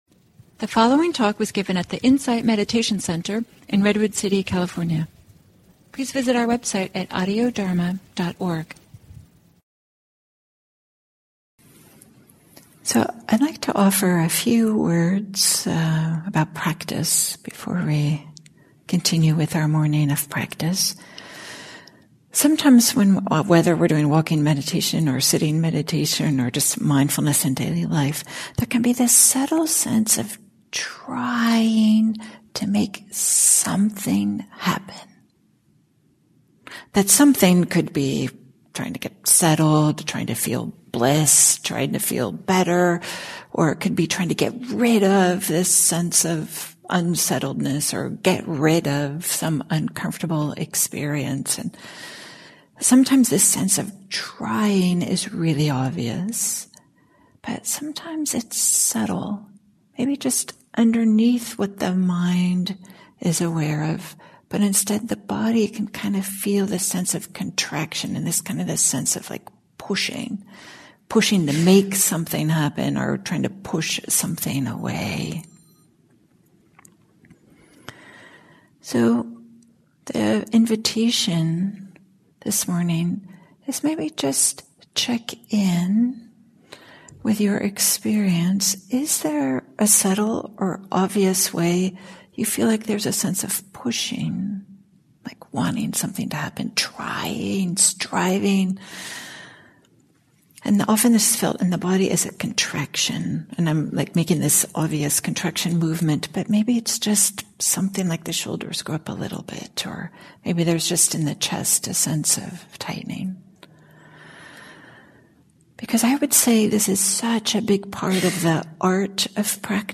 at the Insight Meditation Center in Redwood City, CA.